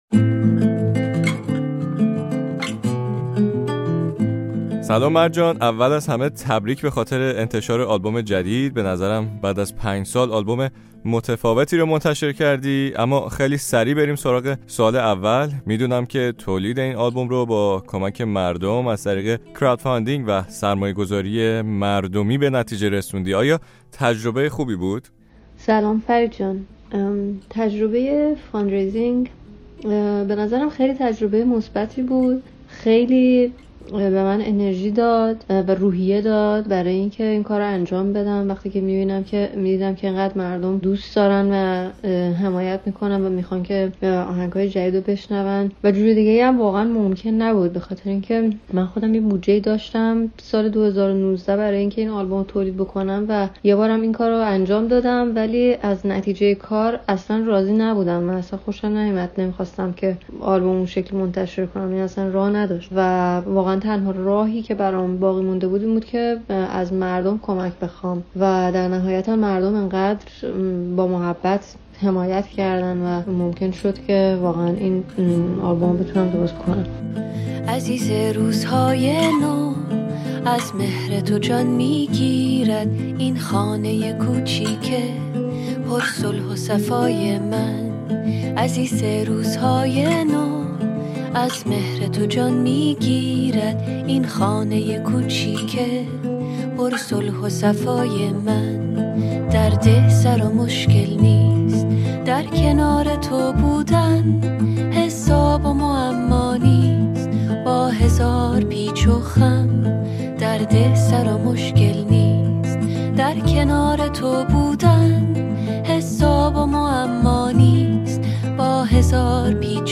مرجان فرساد، خواننده ایرانی ساکن آمریکا، به تازگی آلبوم جدیدی را به عنوان «درخت سپید» منتشر کرده است. با مرجان که در دوره تولید این آلبوم از بیماری سرطان خود با خبر شد و به تازگی در یک عمل جراحی بر این بیماری فائق آمده، در مورد این آلبوم، و نیز فعالیت‌های دیگر او در زمینه انیمیشن گفت‌وگو کرده‌ایم.